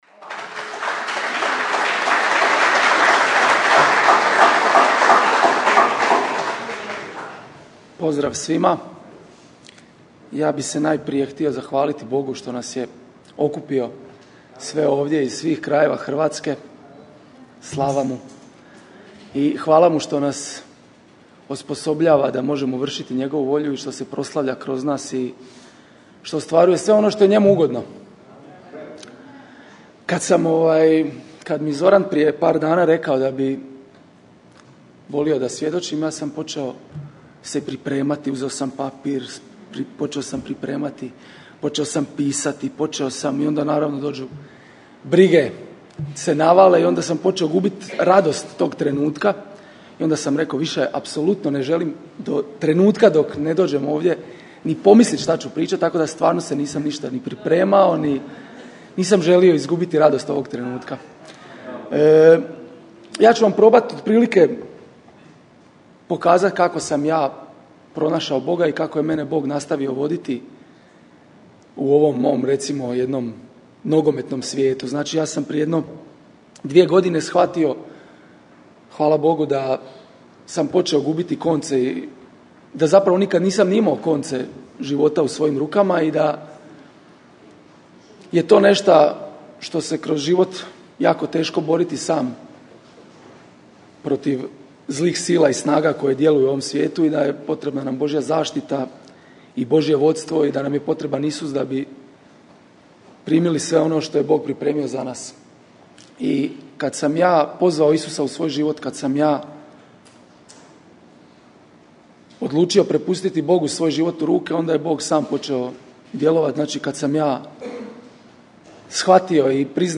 Svjedočanstva - Sve 5
Konferencija "Skupljanje Orlova" IMPACT Centar Zadar, 08.06.2013.